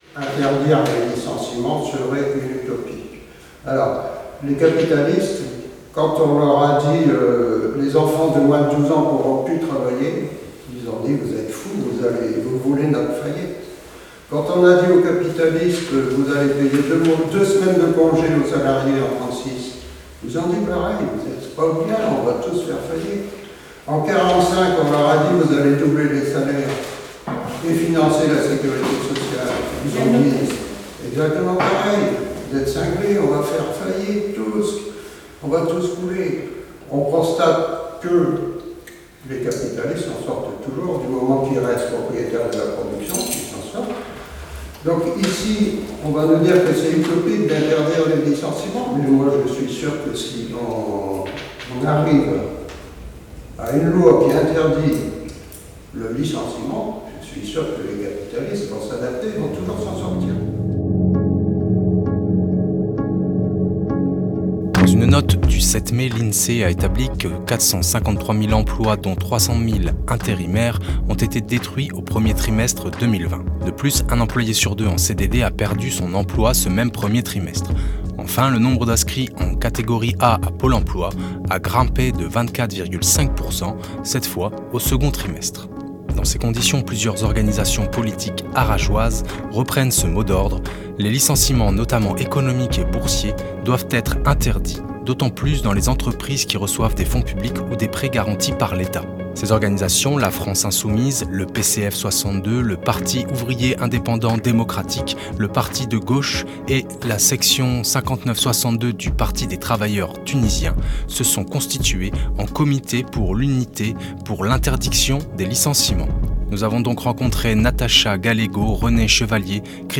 Nous avons rencontré plusieurs membres du C.U.P.I.L lors d’une conférence de presse donné le 25 août à Arras.